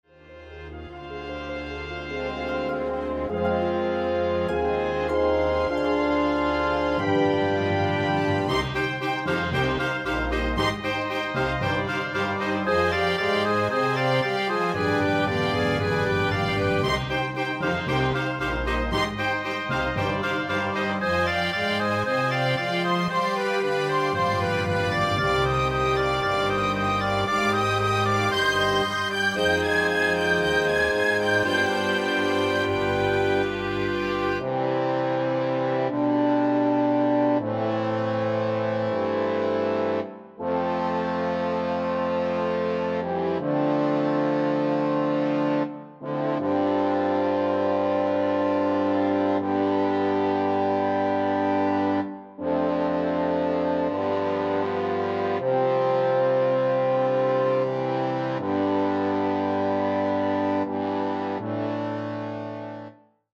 a decir del autor de la versión orquestal